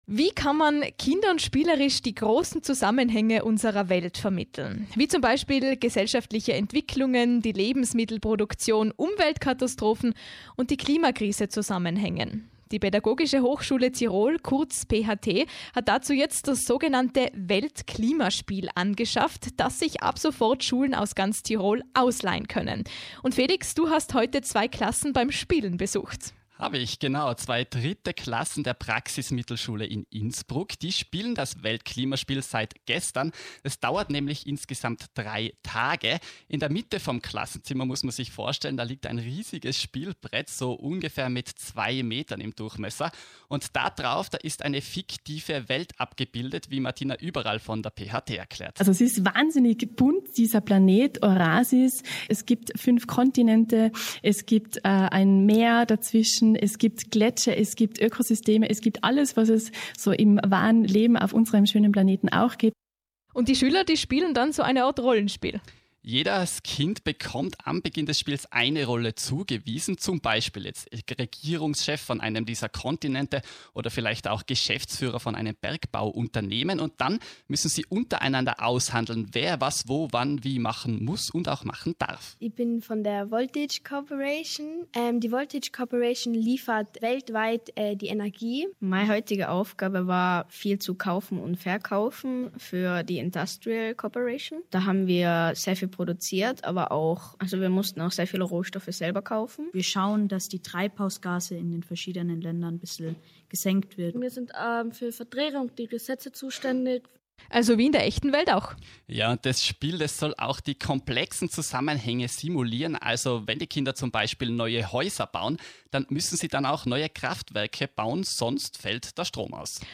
Radiobeitrag U1-Weltklimaspiel an der PH-Tirol
Reportage über das Weltklimaspiel, das Anfang Juni an der PH
Welt_Klima_Spiel_Radio_U1_Tirol_ohne_Musik.mp3